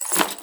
MetalInventoryClink.wav